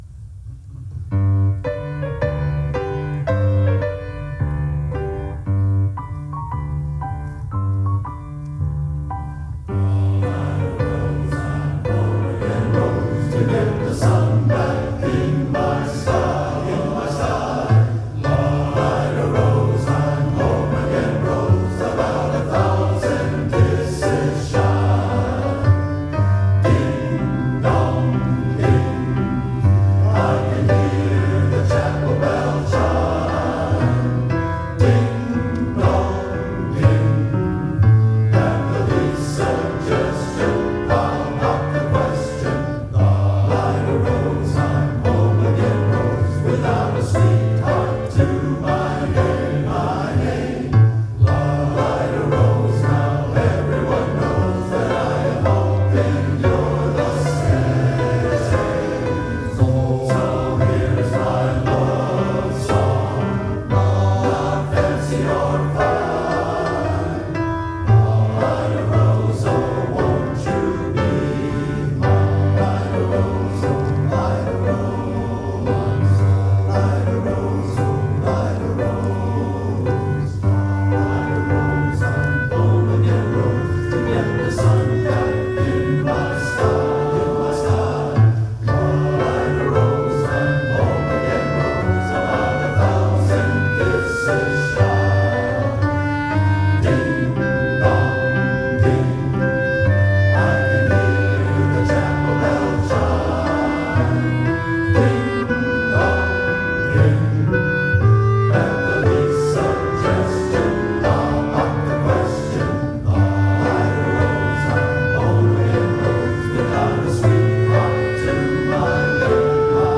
First Congregational Church Of Southington, Connecticut - April 22, 2023